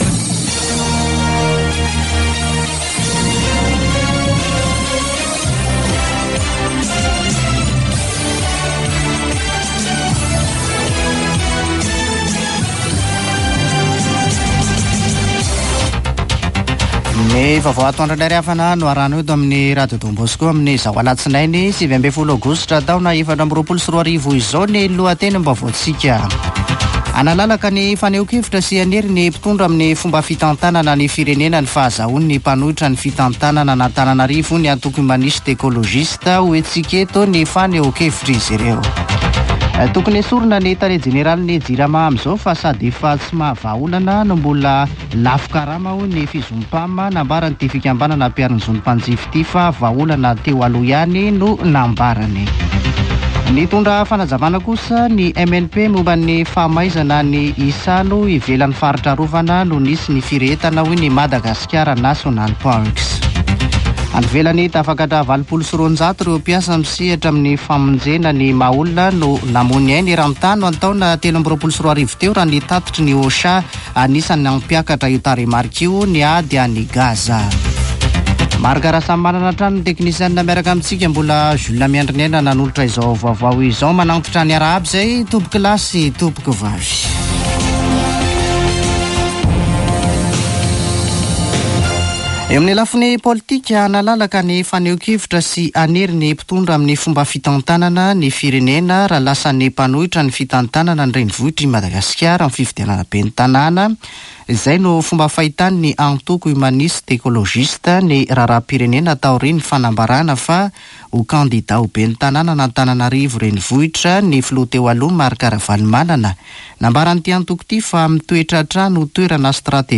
[Vaovao antoandro] Alatsinainy 19 aogositra 2024